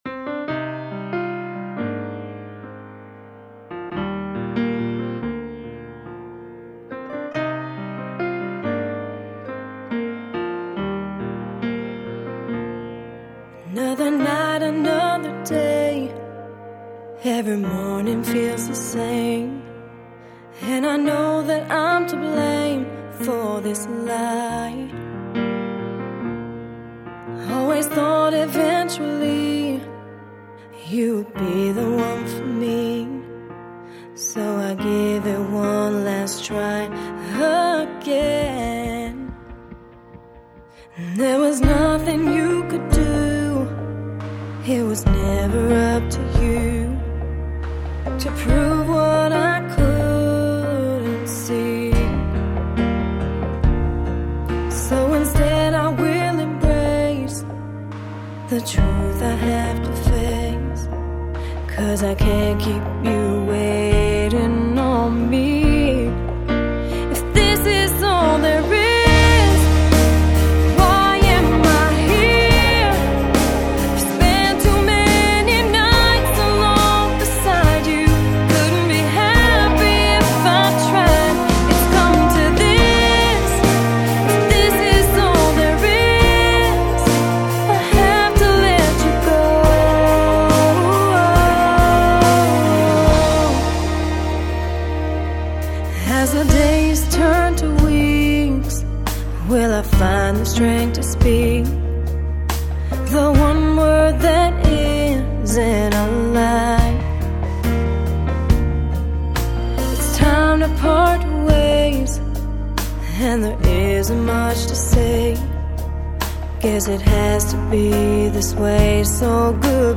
(pop/AC)